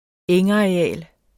Udtale [ ˈεŋ- ]